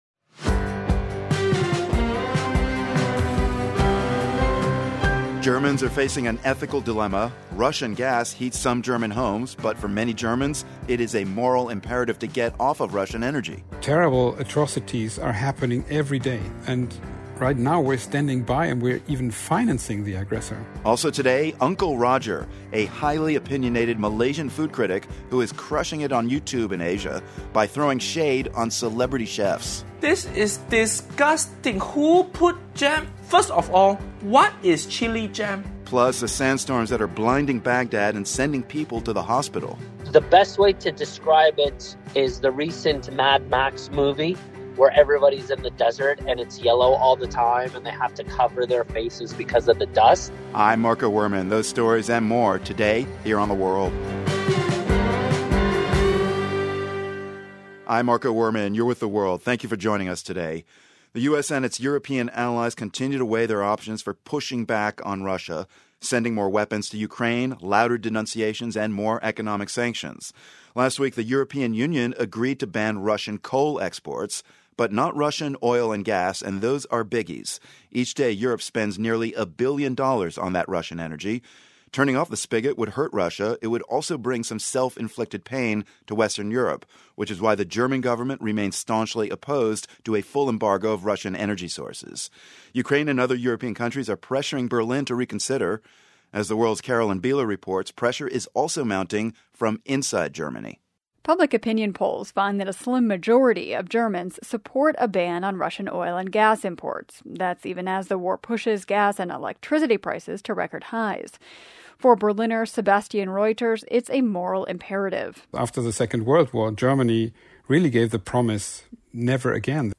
US President Joe Biden has called Russian war atrocities in Ukraine "genocide." We hear from a genocide scholar who agrees. And a growing number of Haitians have been arriving on the coast of Florida since last fall.